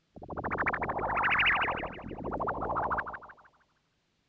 Frogs.wav